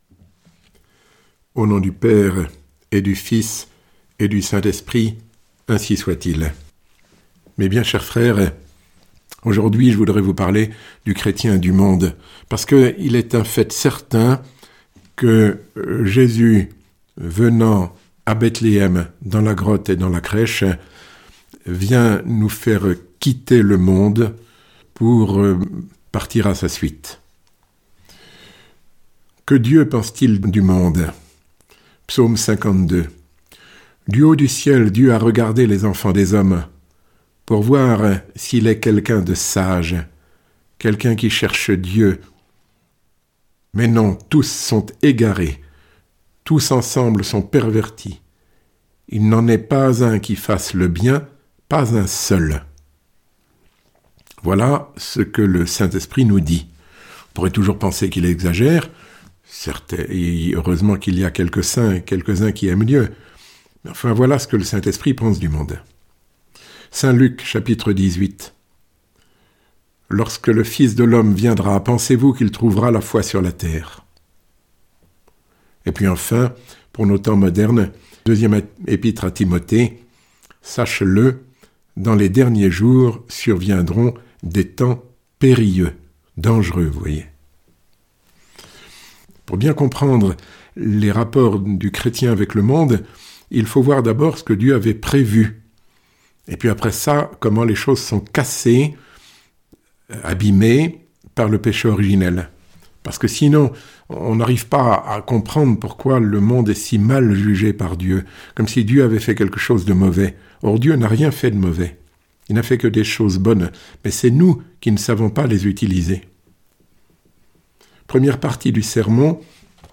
Sermon Les œuvres de Dieu ~ 14 L’homme maître du monde ou son esclave ?